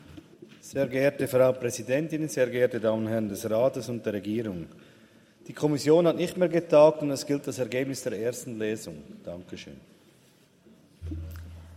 18.9.2023Wortmeldung
Session des Kantonsrates vom 18. bis 20. September 2023, Herbstsession